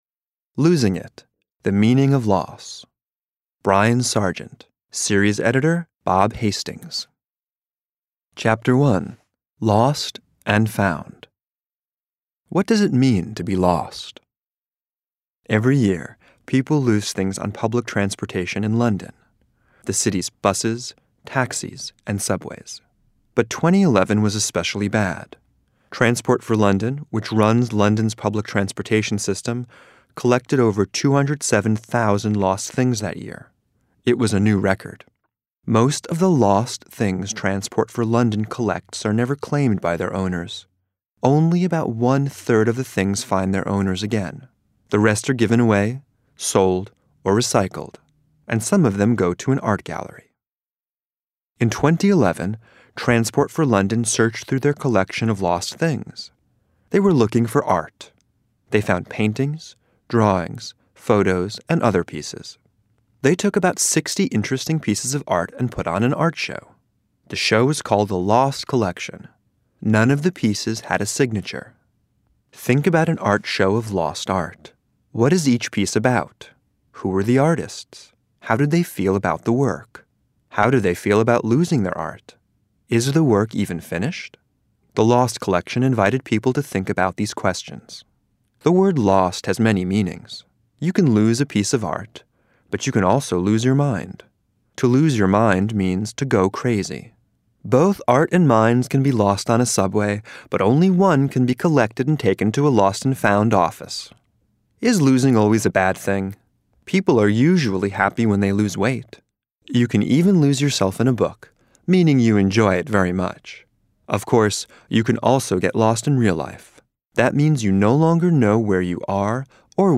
Type : Short Story